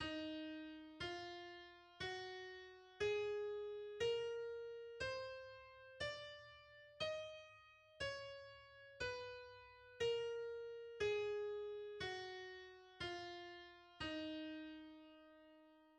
E-flat minor
E-flat minor is a minor scale based on E, consisting of the pitches E, F, G, A, B, C, and D. Its key signature consists of six flats.
The E-flat harmonic minor and melodic minor scales are: